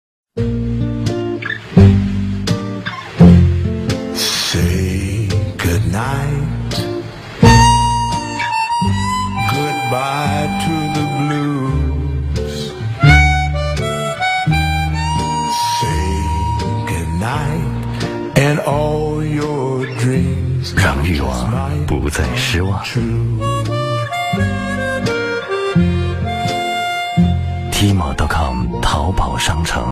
男